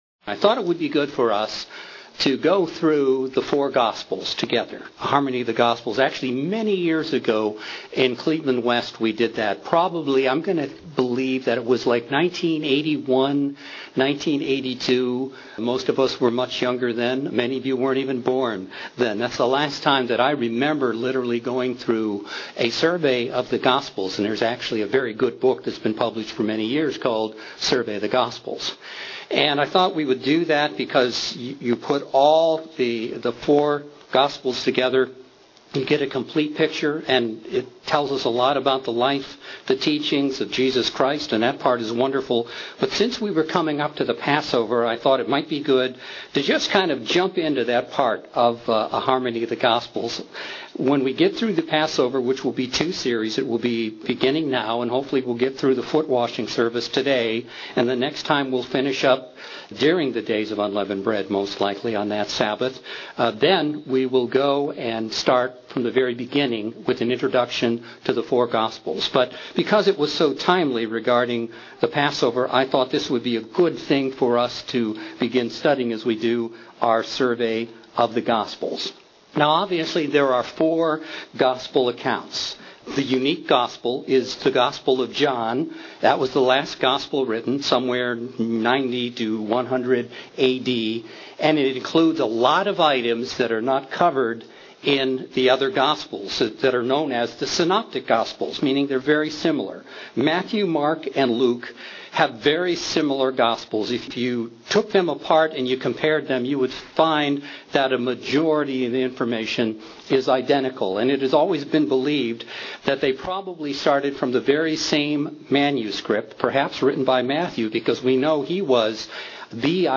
The first of a two-part Bible study series on Passover. This study focuses on the footwashing part of the new covenant Passover service, at its institution and its meaning for us today.